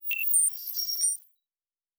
pgs/Assets/Audio/Sci-Fi Sounds/Electric/Data Calculating 2_5.wav at 7452e70b8c5ad2f7daae623e1a952eb18c9caab4
Data Calculating 2_5.wav